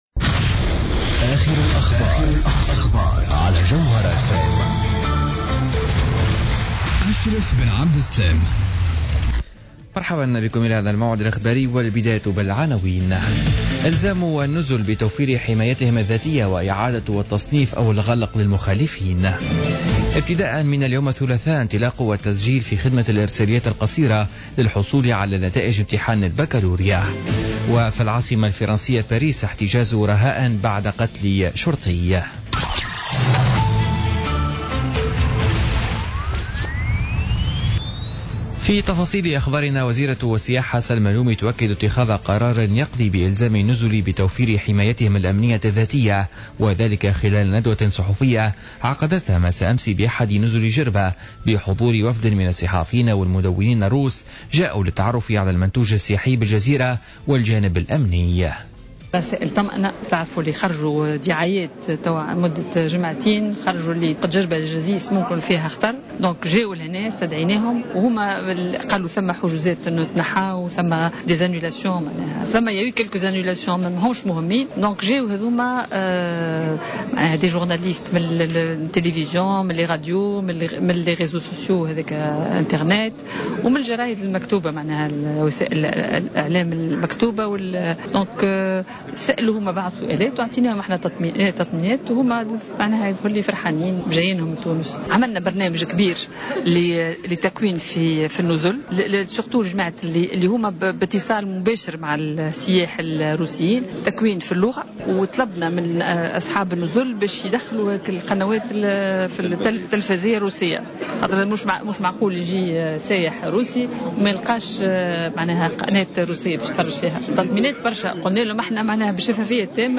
نشرة أخبار منتصف الليل ليوم الثلاثاء 14 جوان 2016